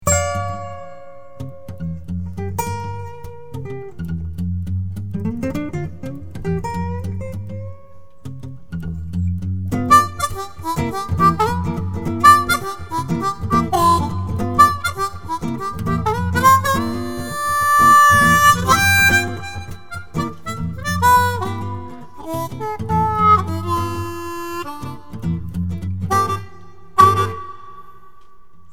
2ndポジション ・・・ とにかくブルージー